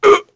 Crow-Vox_Damage_02.wav